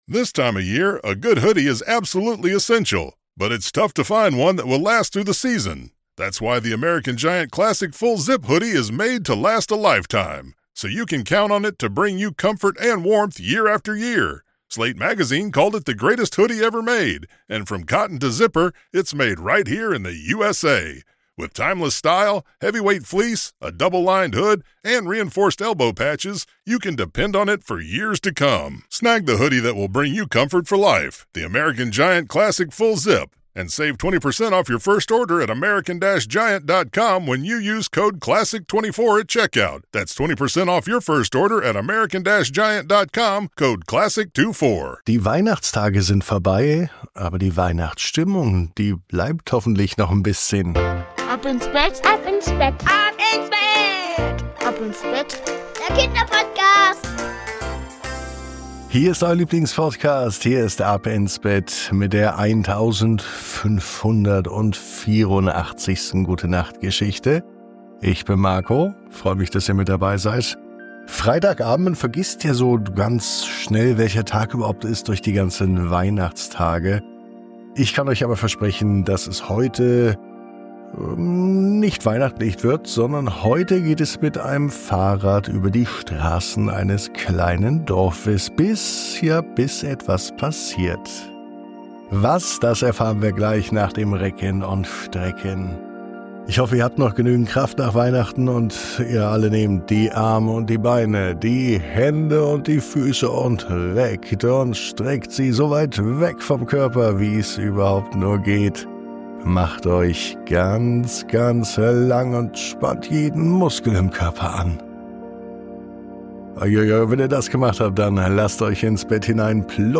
Perfekt zum Einschlafen: Mit liebevollen Beschreibungen, einem beruhigenden Verlauf und einer inspirierenden Botschaft ist diese Episode genau das Richtige, um in den Schlaf zu finden.